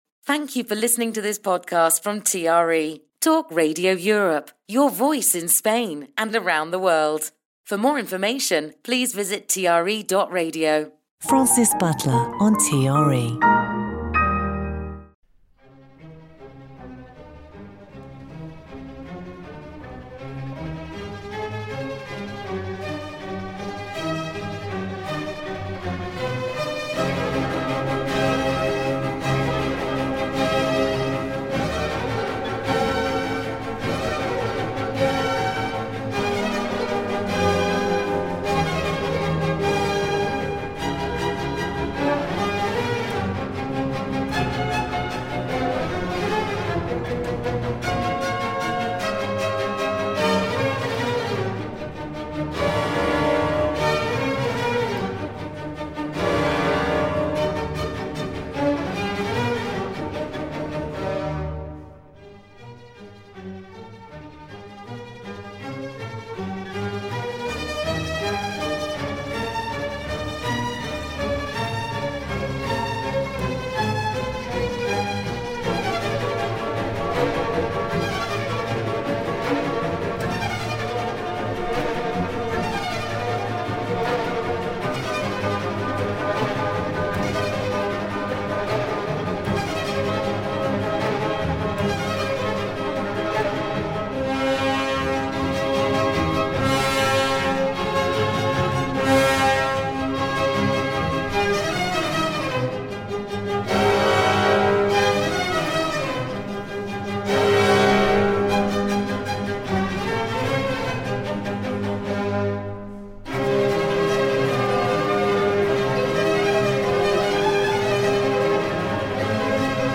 Classical Music Show